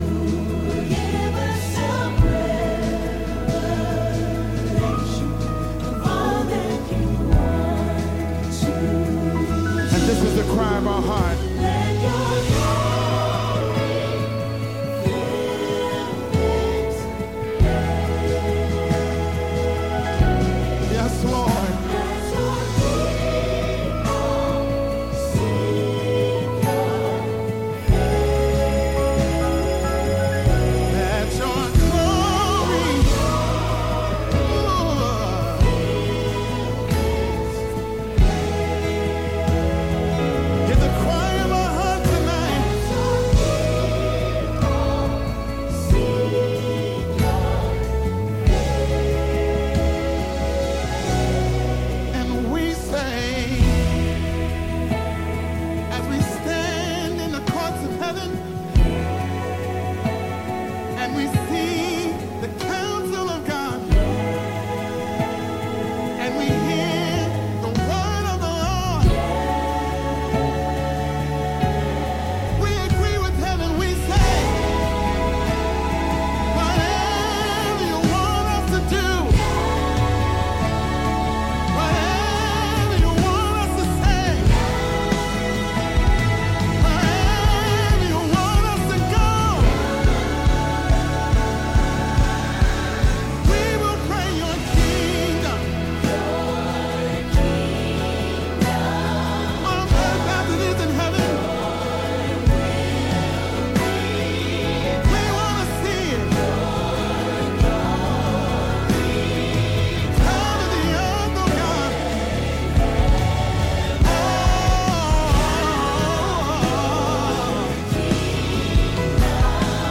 Teaching on Bridging the Gap between Generations and what they Need in their spiritual walk with the Lord.